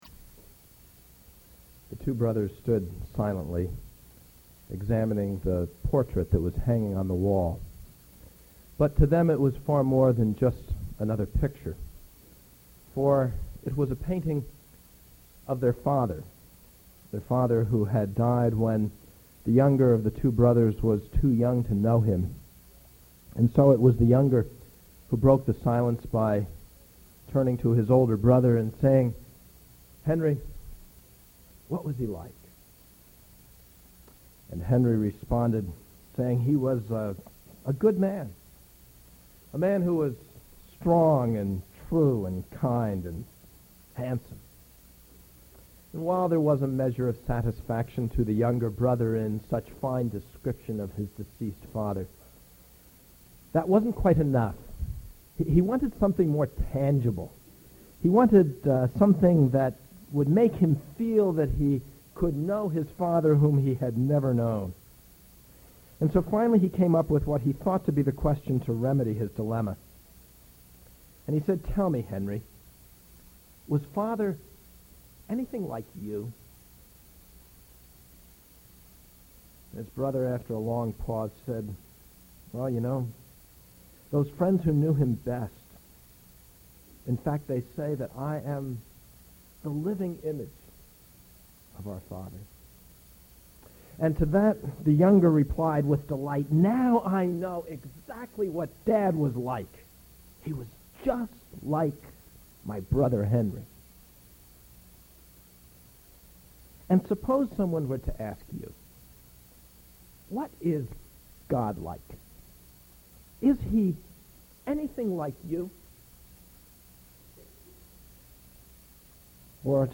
Search All Browse By Date Current Sermon What is Godliness?